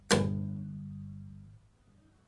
双重低音：单音 Col Legno G1 G3 " 双重低音 G1 Collegno
描述：这是个低音大提琴的乐器样本，演奏的是Collegno。 使用的调音频率（音乐会音高）是442，动态意图是夹音。这个样本属于一个多样本包 乐队乐器。双重低音乐器。和弦乐和弦乐。弦乐器音符：G八度。1音乐会音高：442Hz动态：Mezzoforte（扩展）技术。技术：Collegno麦克风。ZoomH2N话筒设置。XY
标签： 多重采样 放大H2N COL-LEGNO mezzoforte 克锐-1 串仪器 弦鸣乐器 低音提琴
声道立体声